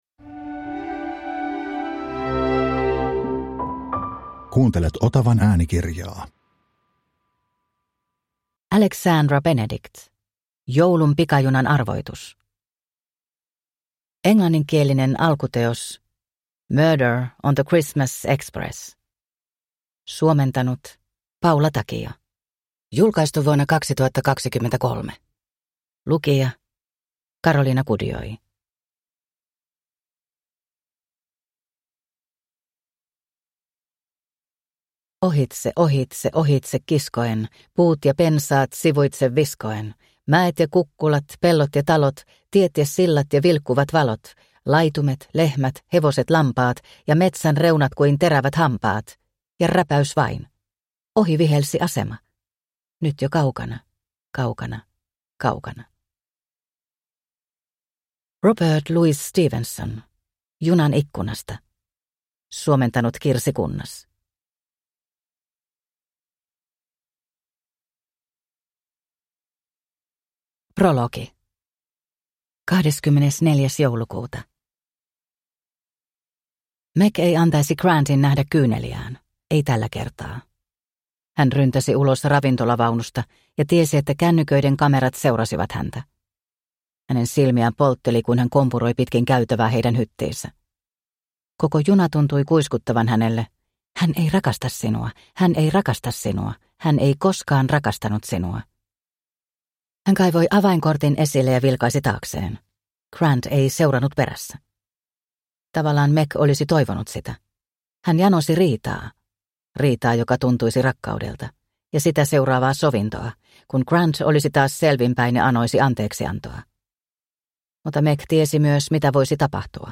Joulun pikajunan arvoitus – Ljudbok – Laddas ner